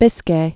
(bĭskā), Bay of